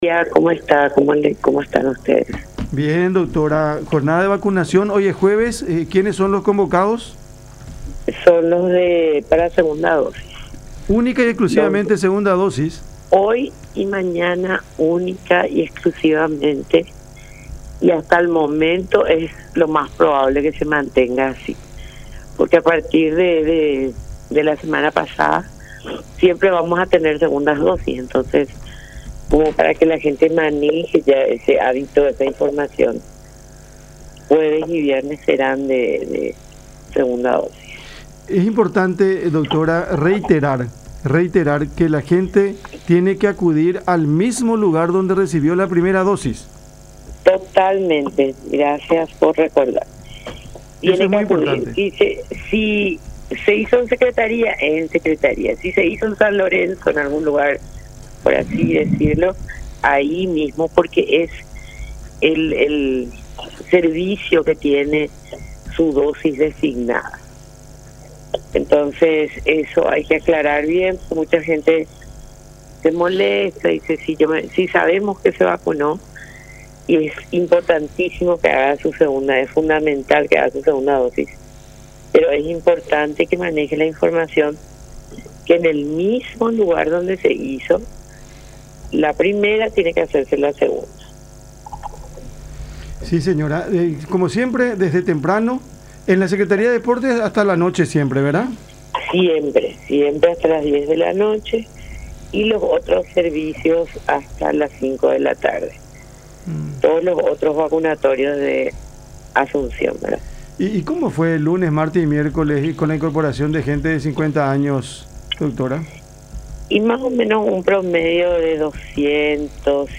en contacto con Cada Mañana por La Unión